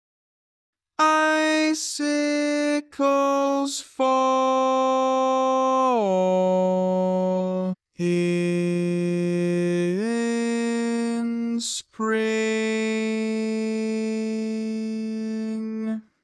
Type: Barbershop
Each recording below is single part only.